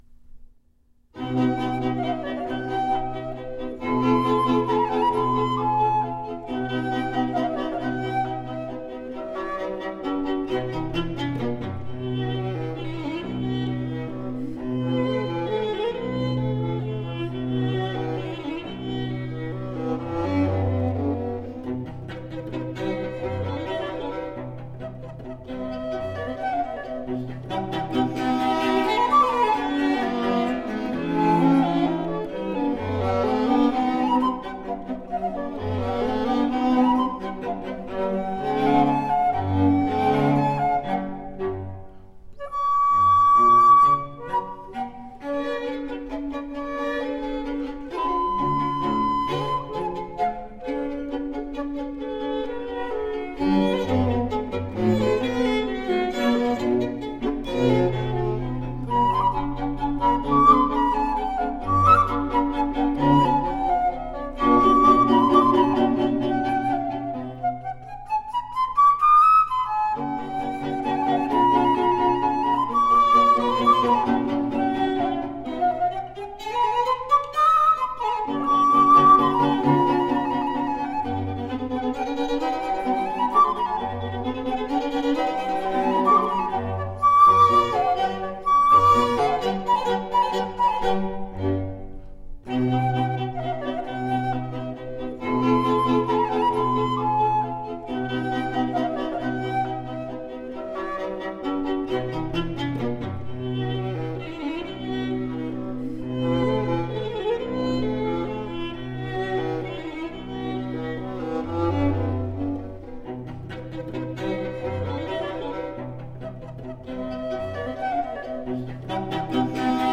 Spectacular baroque and classical chamber music.
Classical, Classical Period, Orchestral, Instrumental, Cello
Flute, Harpsichord, Oboe, Violin